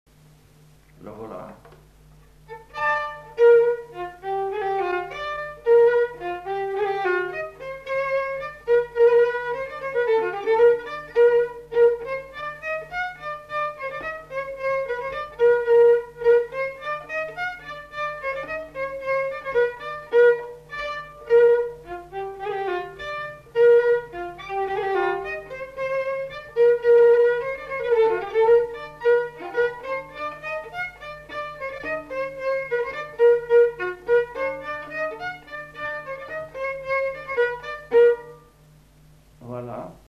Aire culturelle : Gabardan
Genre : morceau instrumental
Instrument de musique : violon
Danse : polka piquée